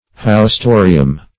Haustorium \Haus*to"ri*um\ (h[add]s*t[=o]"r[i^]*[u^]m), n.; pl.